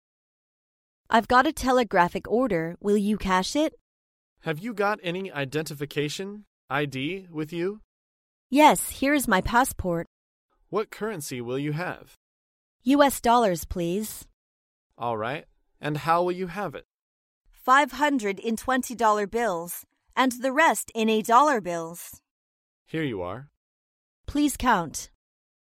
在线英语听力室高频英语口语对话 第437期:电汇兑现的听力文件下载,《高频英语口语对话》栏目包含了日常生活中经常使用的英语情景对话，是学习英语口语，能够帮助英语爱好者在听英语对话的过程中，积累英语口语习语知识，提高英语听说水平，并通过栏目中的中英文字幕和音频MP3文件，提高英语语感。